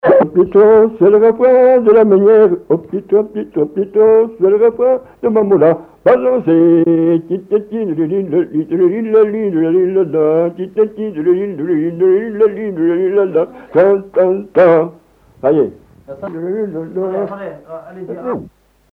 danse : quadrille : galop
accordéon diatonique
Pièce musicale inédite